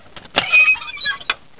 Ton Tuer.wav